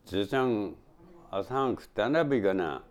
Type: Yes/no question
Final intonation: Falling
Location: Showamura/昭和村
Sex: Male